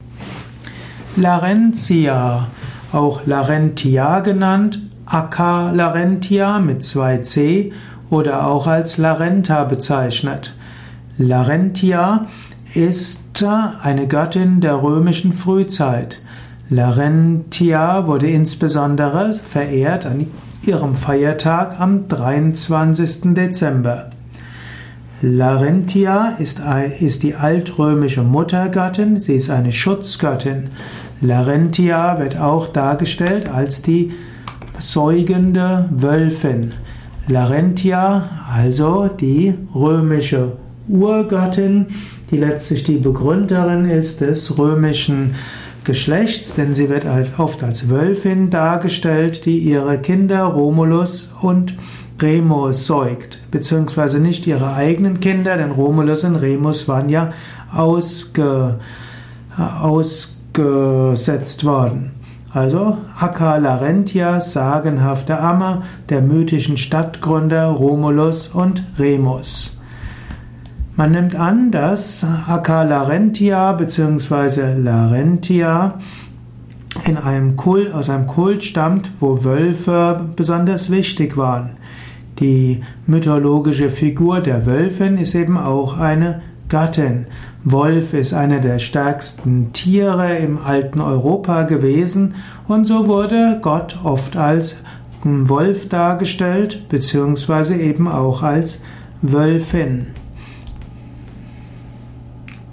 Ein Vortrag über Larentia, einer römischen Göttin. Eruierung der Bedeutung von Larentia in der römischen Mythologie, im römischen Götterhimmel.
Dies ist die Tonspur eines Videos, zu finden im Yoga Wiki.